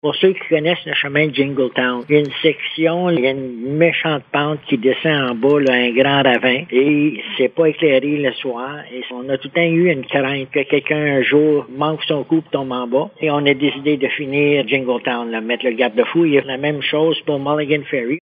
Le maire de Kazabazua, Robert Bergeron, explique qu’un secteur du chemin Jingletown est extrêmement dangereux.